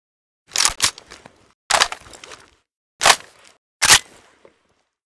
aug_reload_empty.ogg